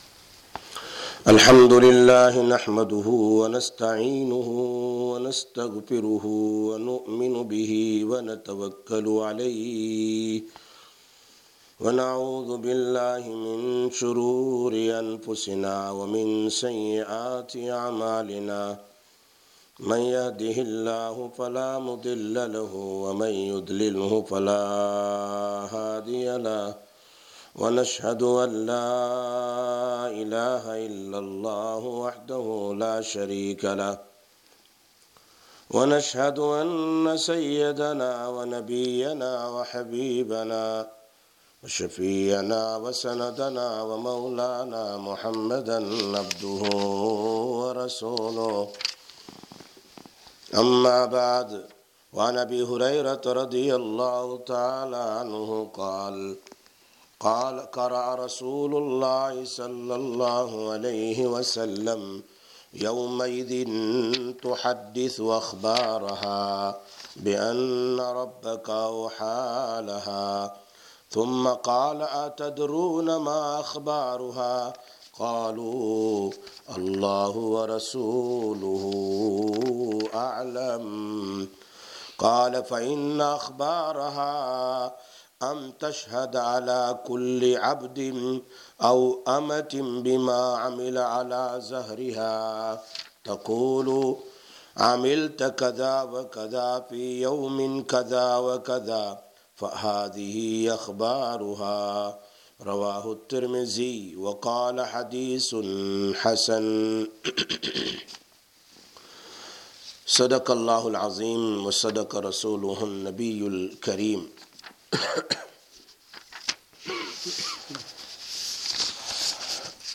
20/02/19 Sisters Bayan, Masjid Quba